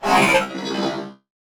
combat / ENEMY / droid / hurt2.wav
hurt2.wav